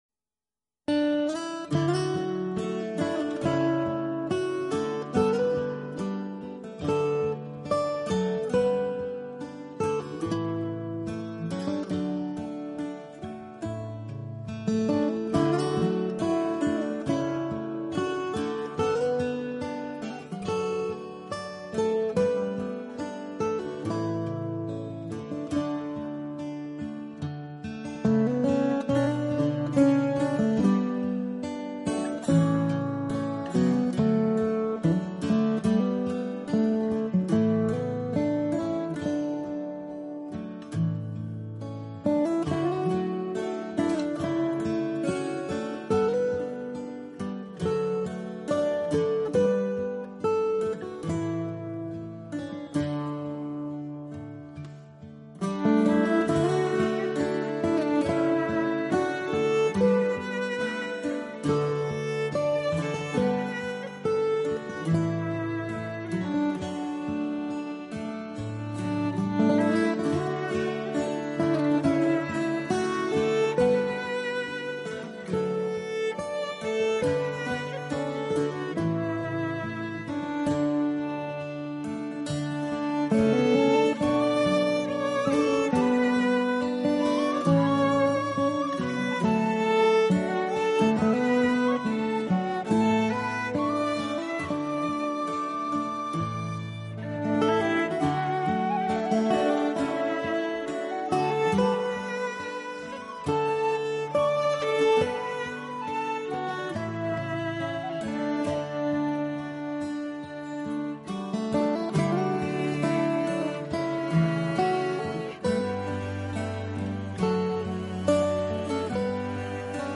音乐风格: Celtic